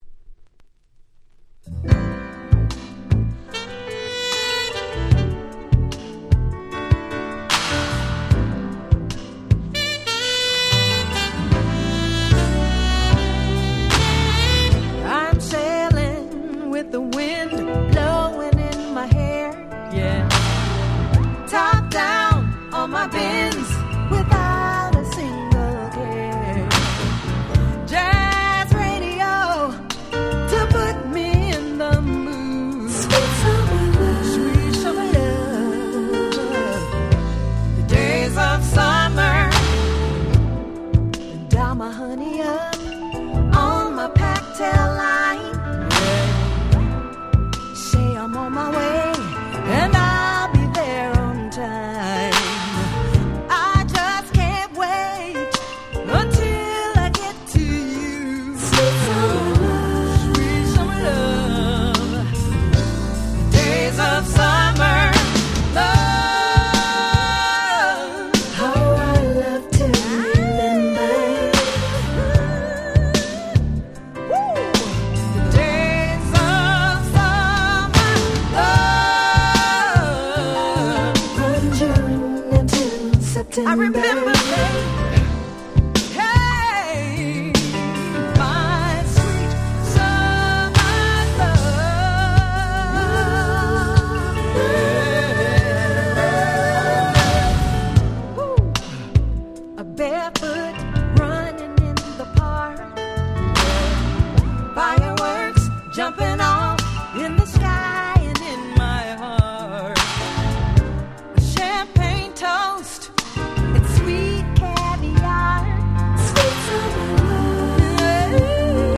94' Very Nice R&B / Slow Jam !!